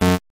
grocy / public / uisounds / error.mp3
error.mp3